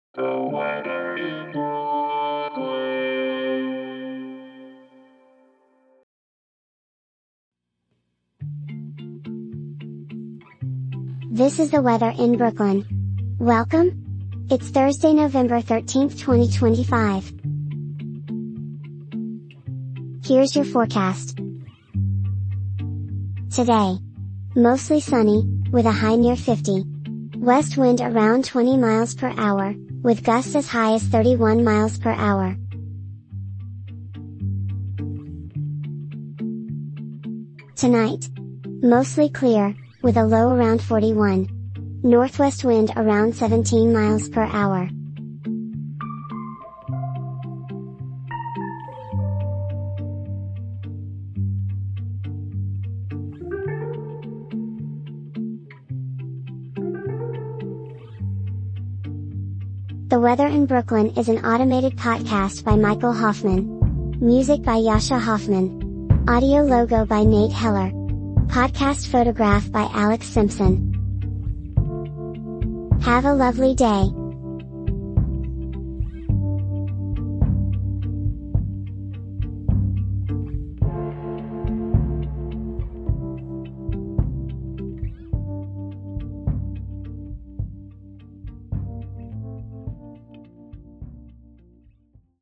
is generated automatically